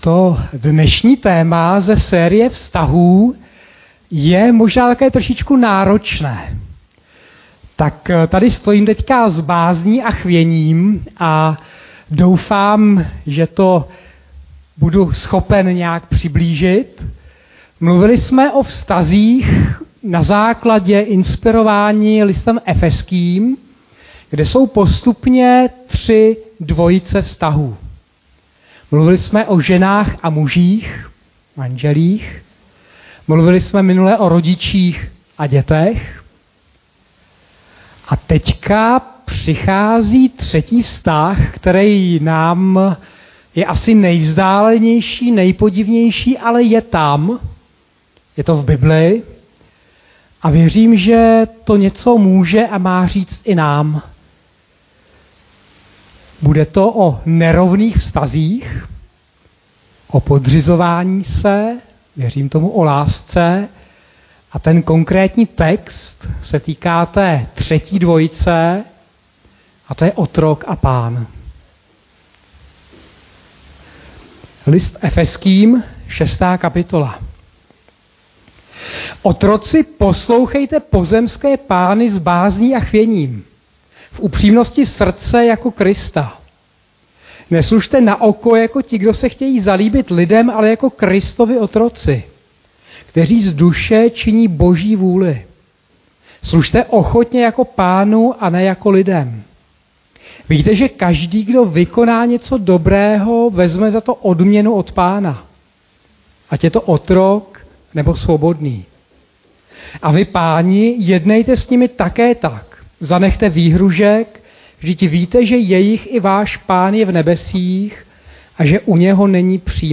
Kázání - Strana 15 z 124 - Západ